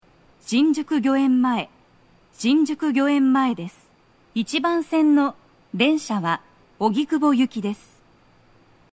mshinjukugyoemmae1toucyakuogikubo.mp3